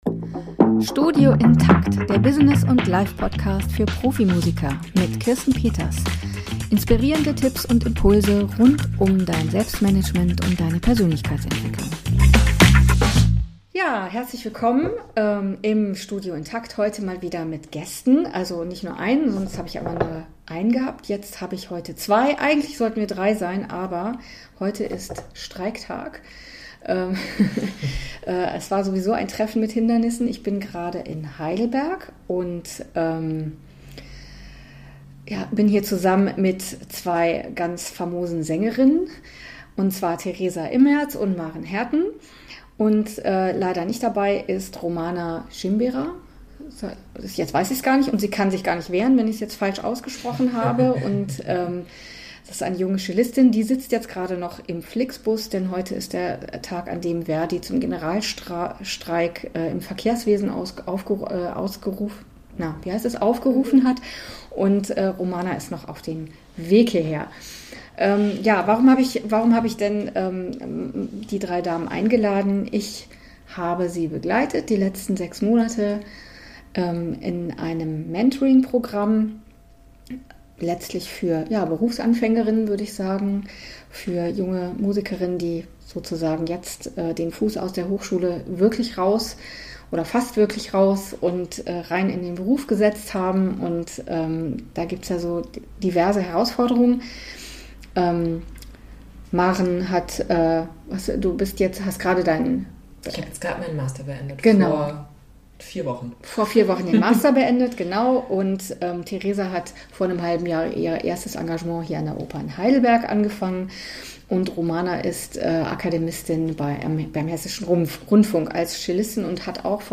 Beide wunderbare Sängerinnen und inspirierende Persönlichkeiten. :-) Sie berichten von ihren battles und wins, die Studien-Ende und der Schritt ins erste Festengagement mit sich brachten.